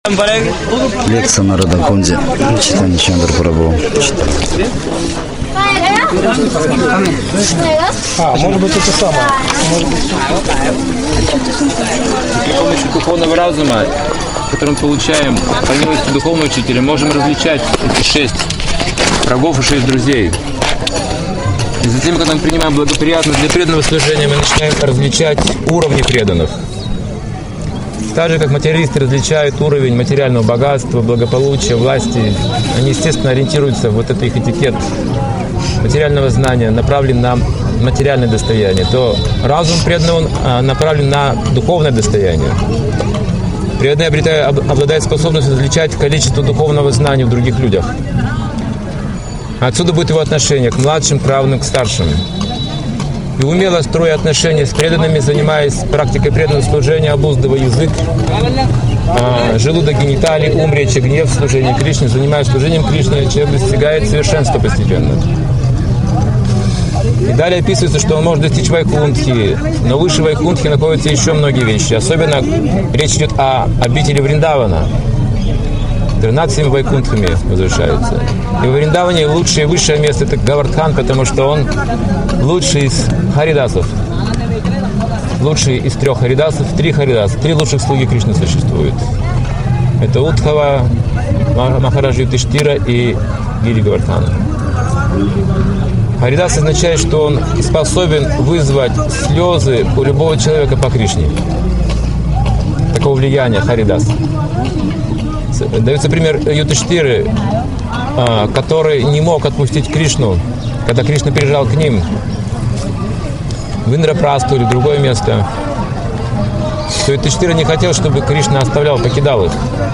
Лекция о самом сокровенном месте на Земле..